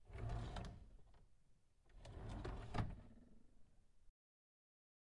描述：一个卧室的声音效果。
声道立体声